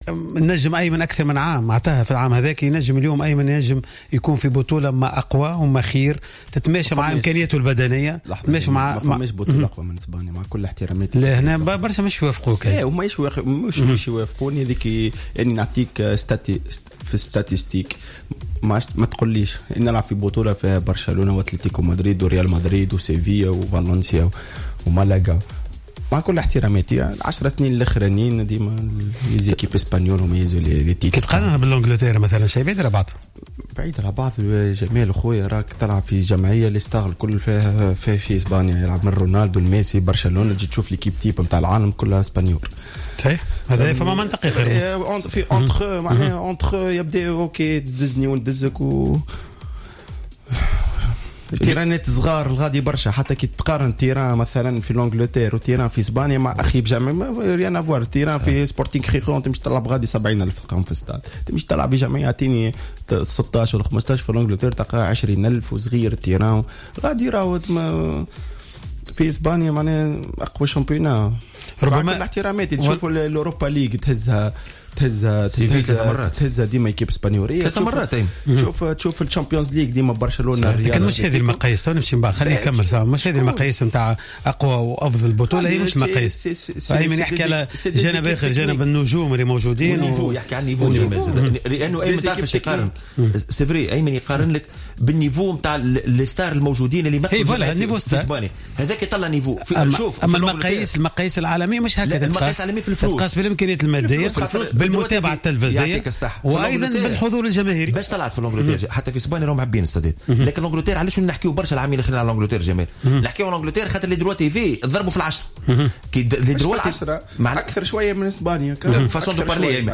أشار لاعب فالنسيا الإسباني أيمن عبد النور في حصة "قوول" أن بطولة الليغا الإسبانية تعد الأقوى في العالم و ذلك بالنظر للنتائج التي تحققها الأندية الإسبانية على مستوى المسابقات إضافة إلى قيمة اللاعبين الذين ينشطون في إسبانيا.